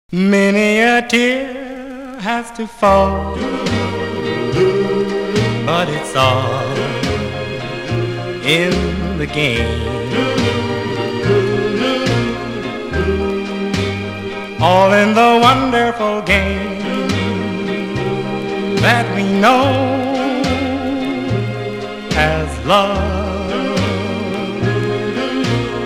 (税込￥3080)   US POP